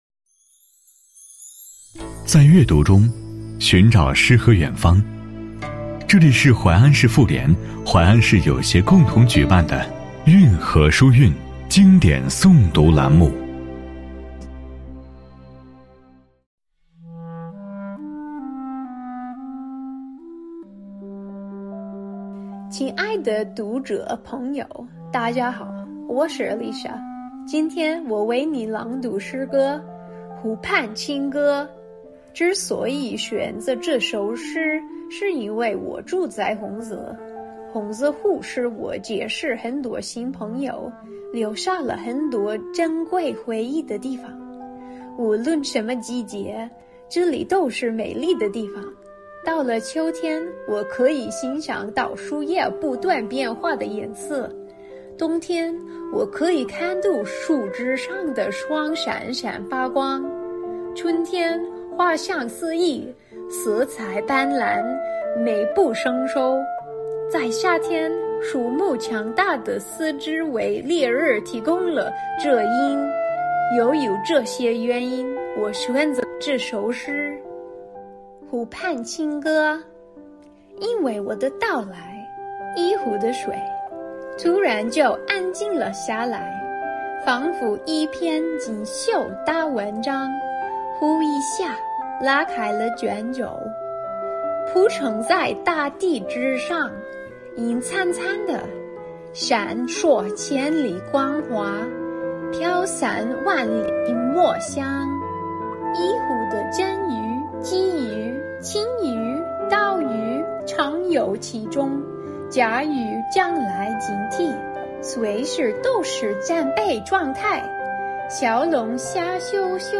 为大力推广全民阅读，进一步推进书香淮安建设、放大“淮安外籍巾帼之家”品牌效应，近期，在市全民阅读办指导下，市妇联和市友协联合知书鱼读书会开设了“运河书韵”经典诵读栏目，每期为您推荐一篇诵读作品、一本经典好书，让书香浸润心灵，让阅读点亮生活！